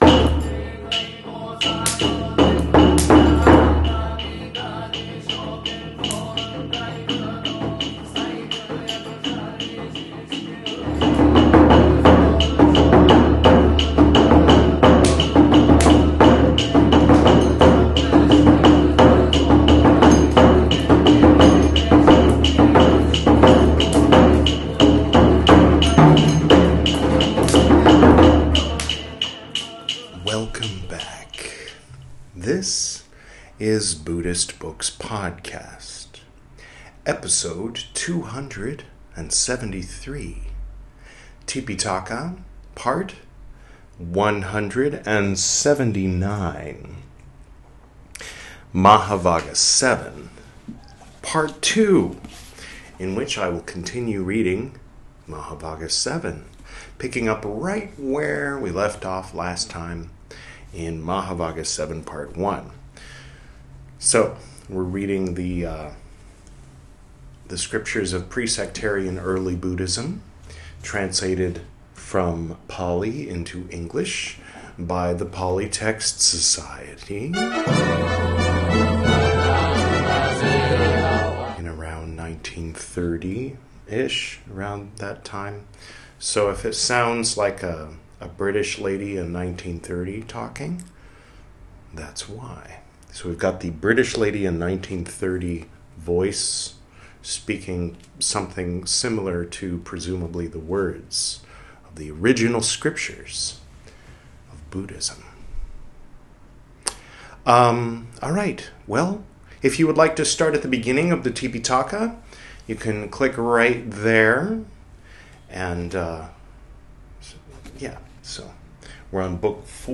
This is Part 179 of my recital of the 'Tipiṭaka,' the 'Three Baskets' of pre-sectarian Buddhism, as translated into English from the original Pali Language. In this episode, we'll continue reading 'Mahāvagga VII,' from the 'Vinaya Piṭaka,' the first of the three 'Piṭaka,' or 'Baskets.'